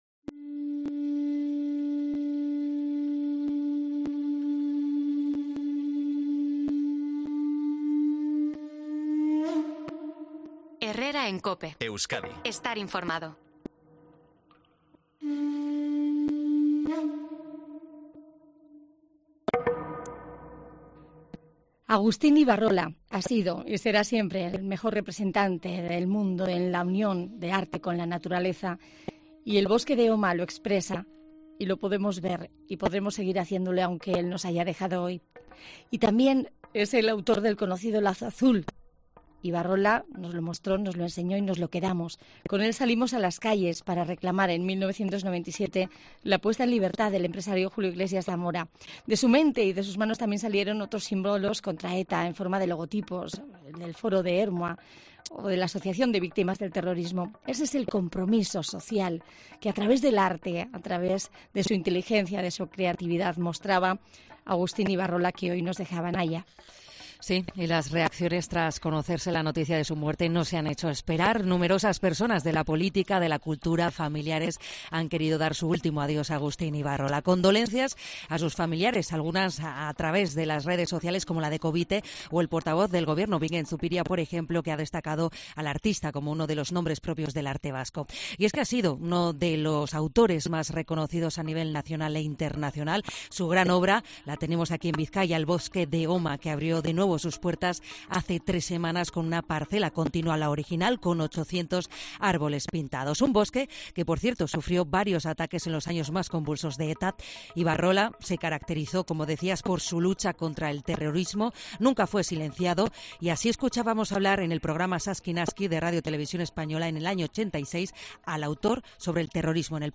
Personalidades de la política y la cultura vasca han pasado por los micrófonos de COPE Euskadi para repasar la vida y obra del artista